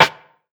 RIM 07    -R.wav